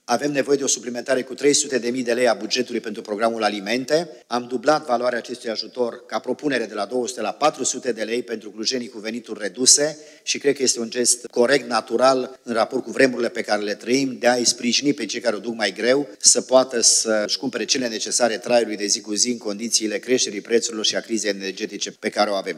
Primarul Emil Boc.